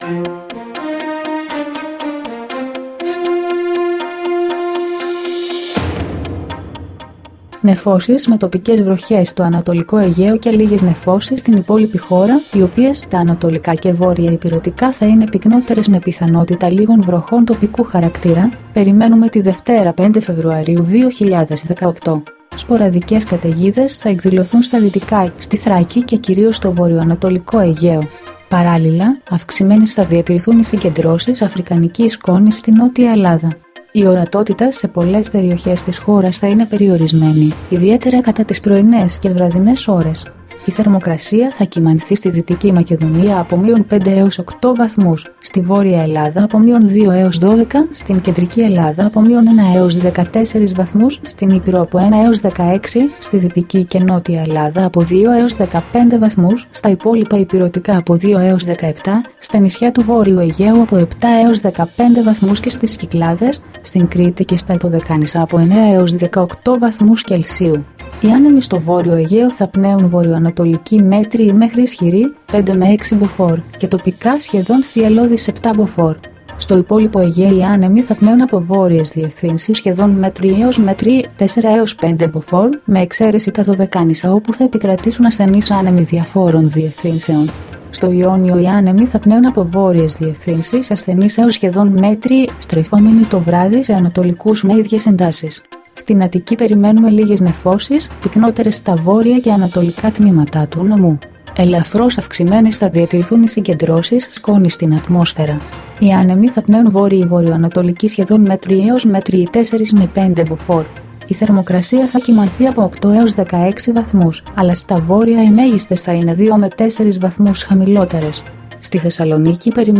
dailyforecast-93.mp3